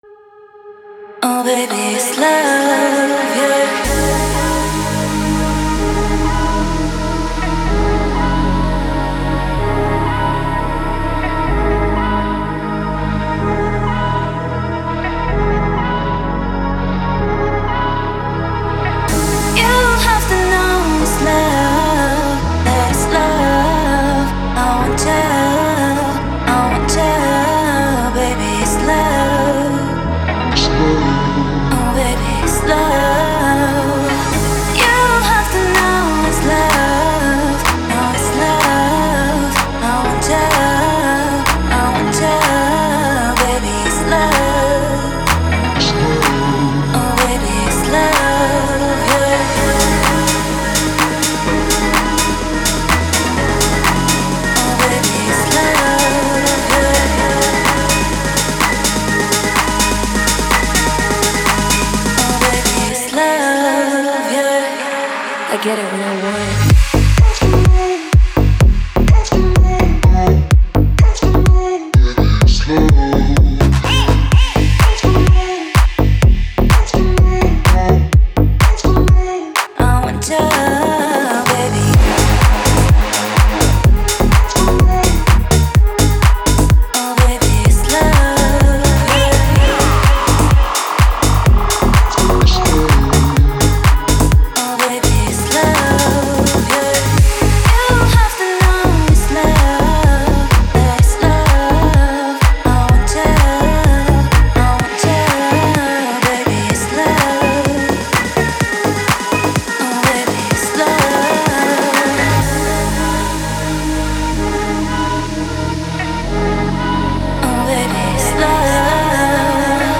который сочетает в себе элементы поп и альтернативного рока.